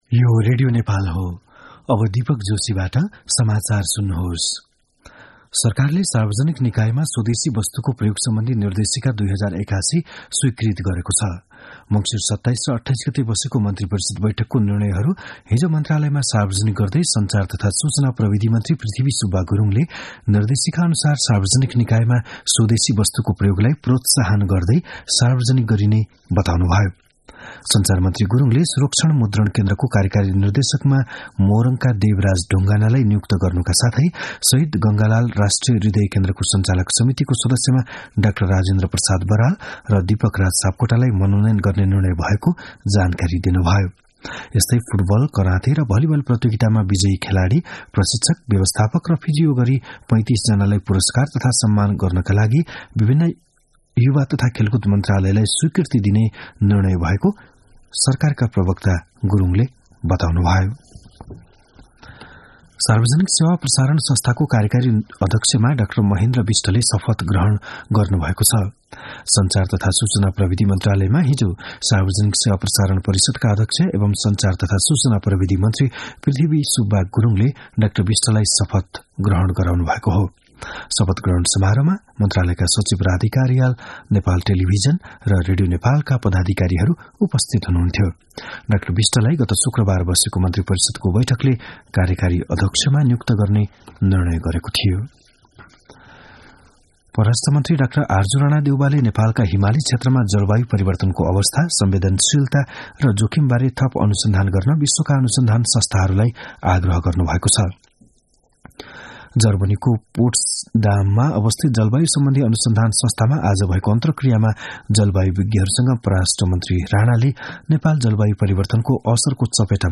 बिहान ११ बजेको नेपाली समाचार : ३ पुष , २०८१
11-am-nepali-news-1-14.mp3